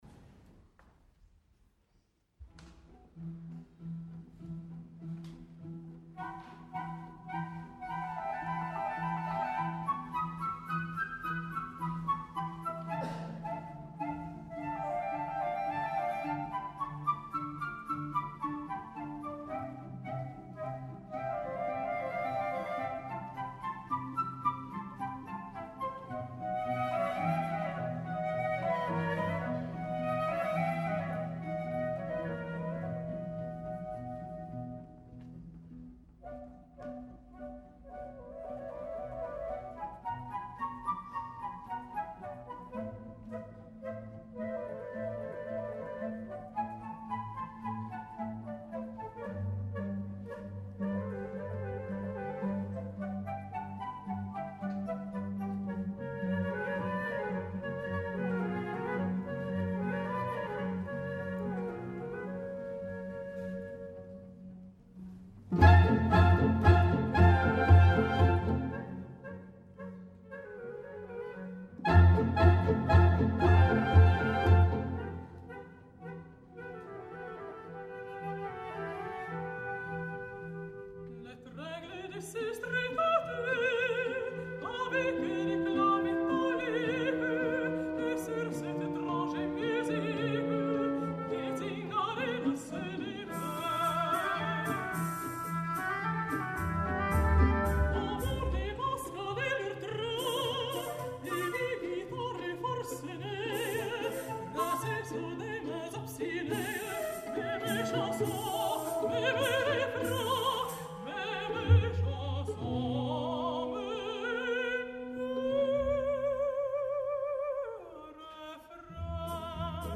al Victoria Hall de Ginebra